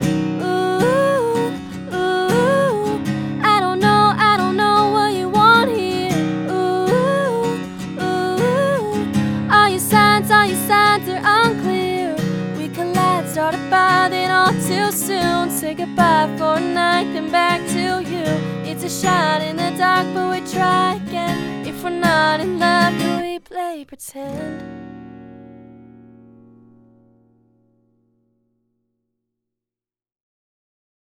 WA87 电容话筒